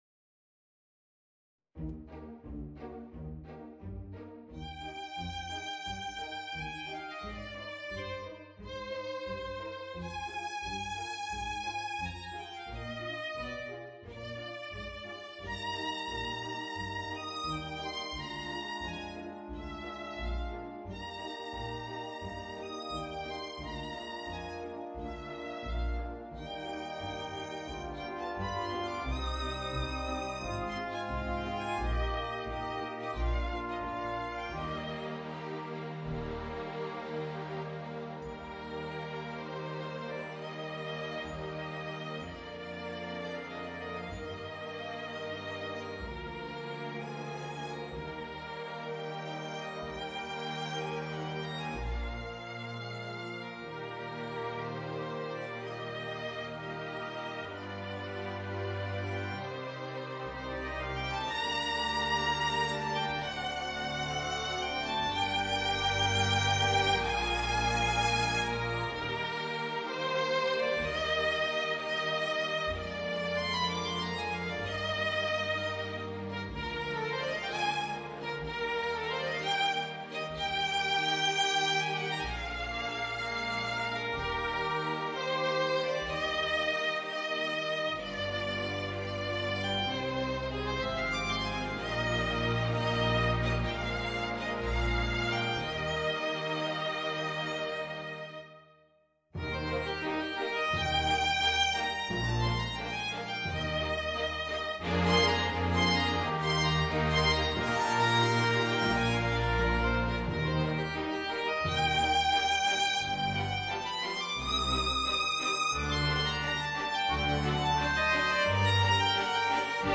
Voicing: Flute and Orchestra